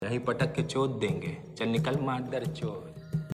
Una frase en hindi popular en internet y redes sociales. Un sonido de meme divertido para reaccionar o enfatizar momentos con humor.